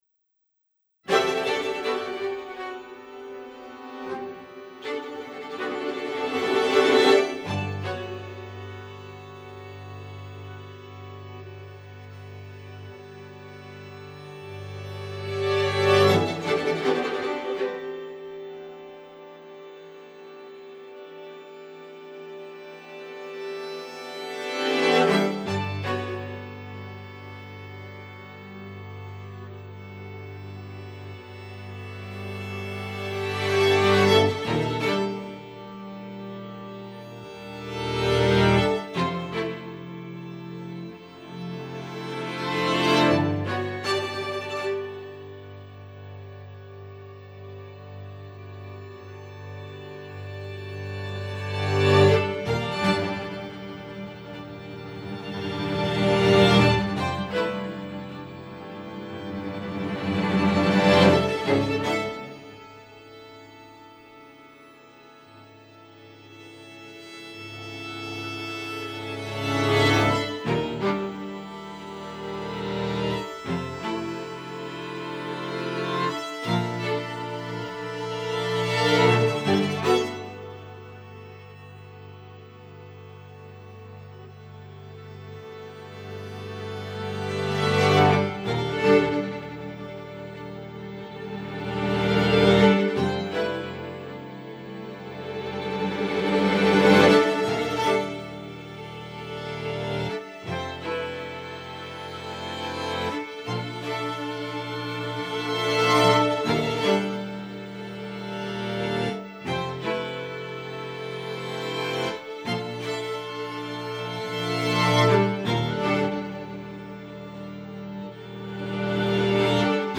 Se grabó en la Ciudad de México en el 2021.
It was recorded in 2021 in Mexico City.